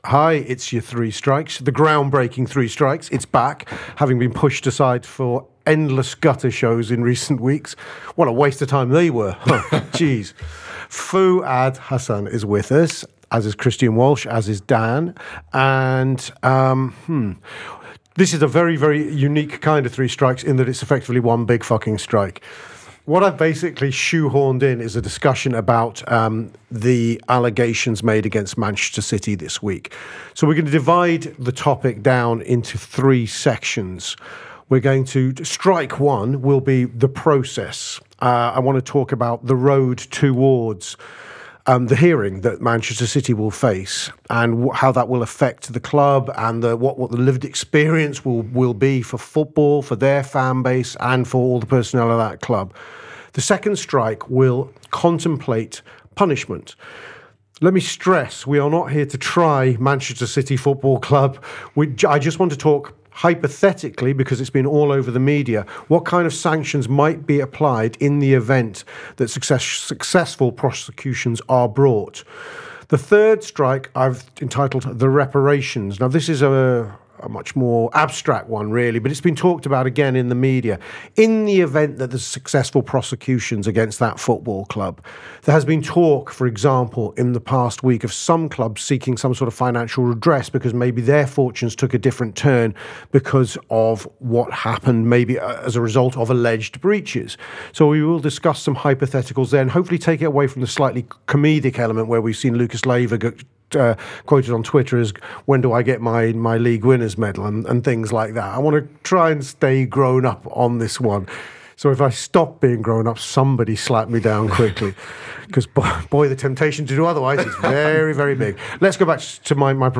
Below is a clip from the show – subscribe for more on Man City’s charges and potential punishment…